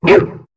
Cri de Ponchien dans Pokémon HOME.